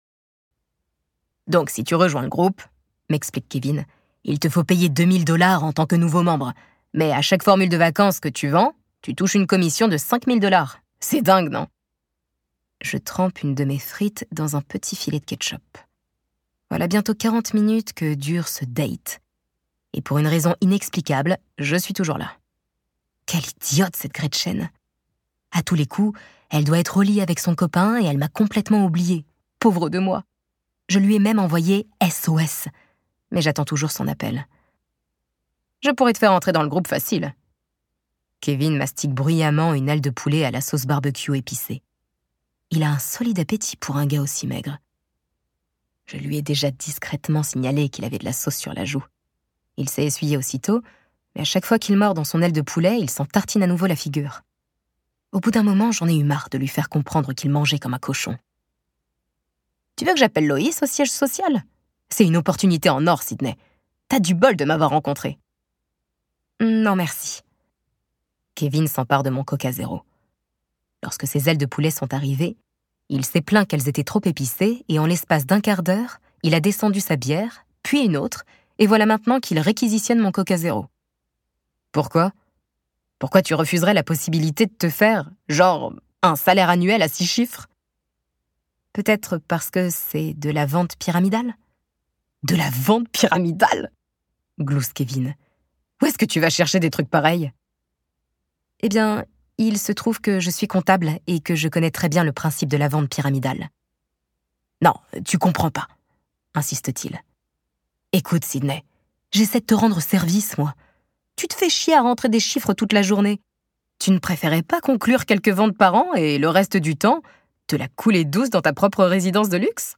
je découvre un extrait